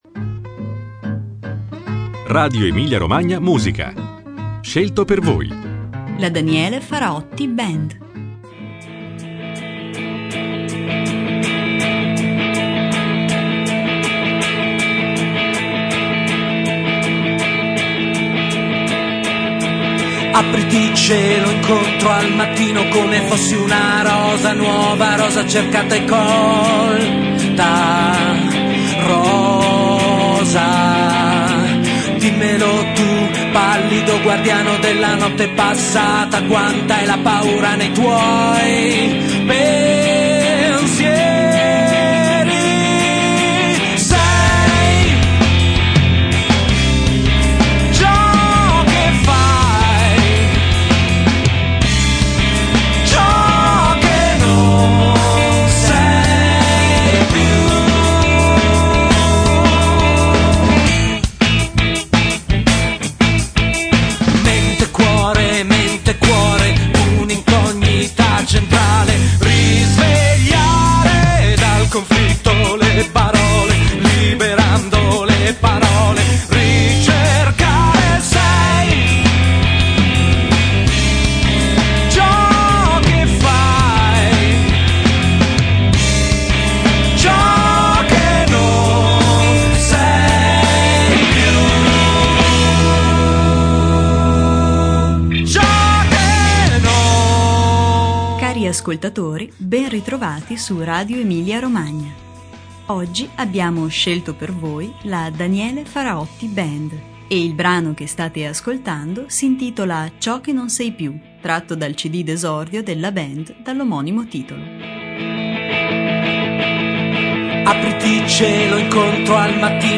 Una particolare alchimia tra indie, rock e progressive